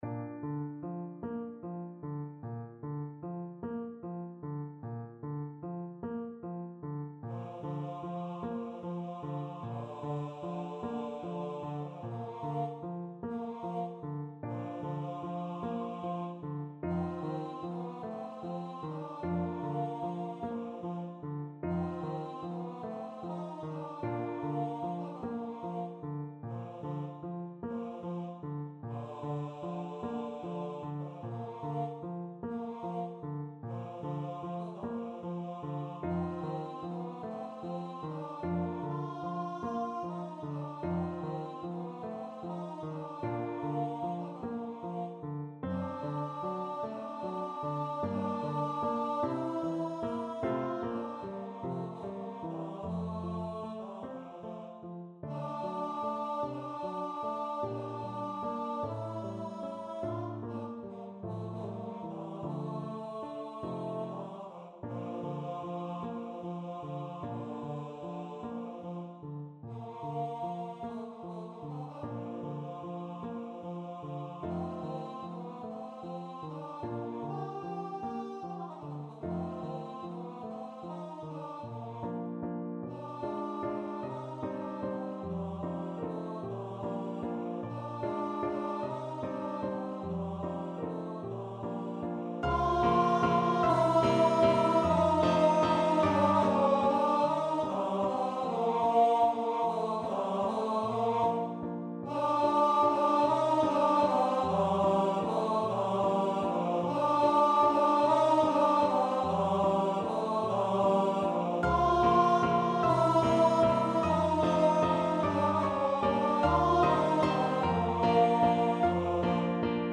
4/4 (View more 4/4 Music)
A3-G5
Largo
Classical (View more Classical Baritone Voice Music)